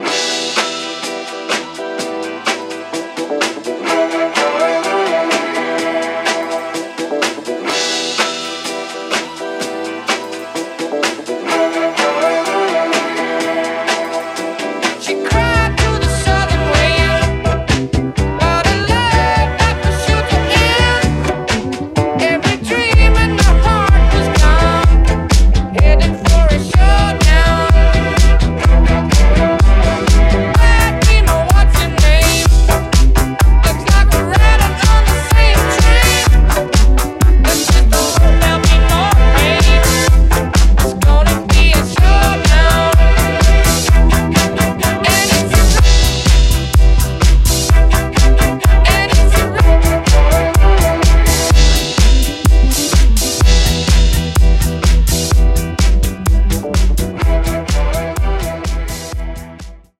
ジャンル(スタイル) DISCO HOUSE